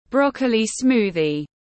Sinh tố súp lơ xanh tiếng anh gọi là broccoli smoothie, phiên âm tiếng anh đọc là /ˈbrɒk.əl.i ˈsmuː.ði/